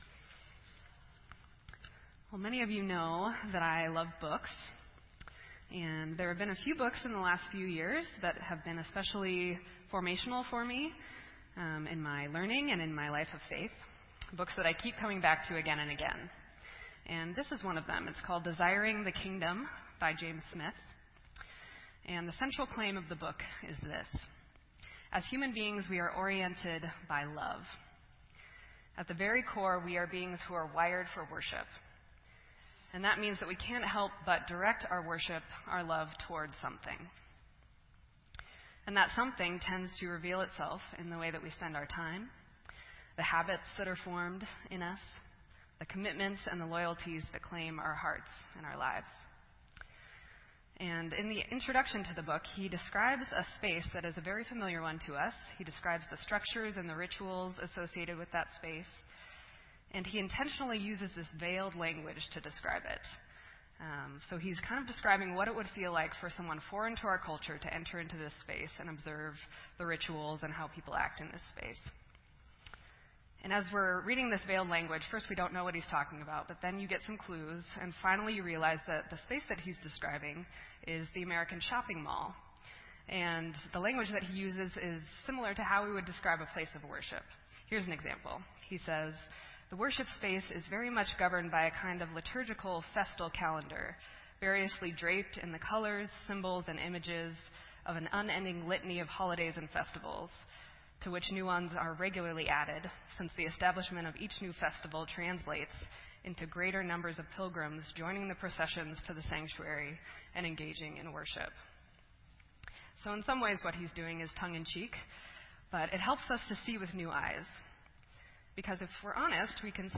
This entry was posted in Sermon Audio on November 20